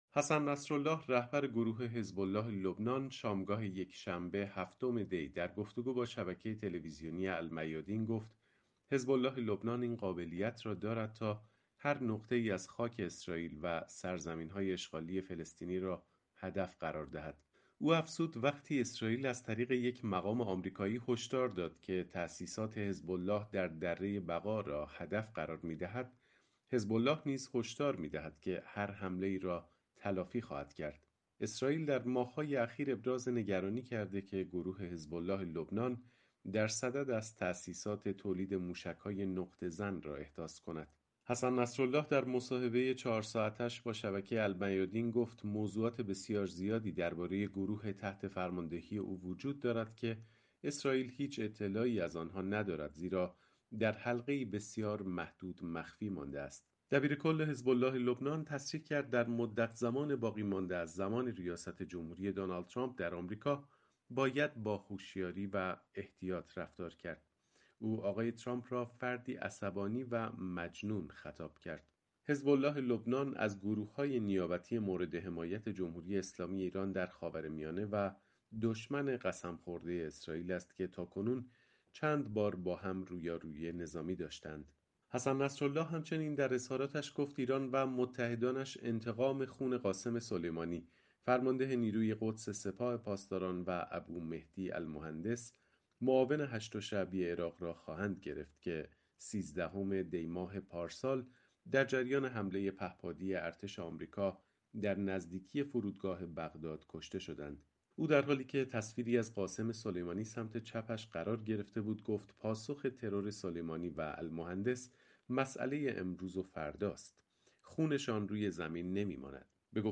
جزئیات بیشتر در گزارشی